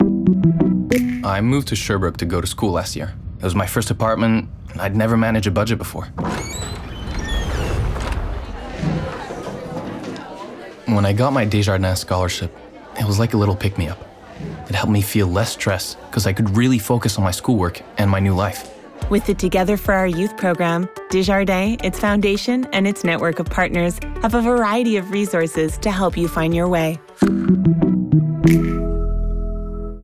Publicité (Desjardins) - ANG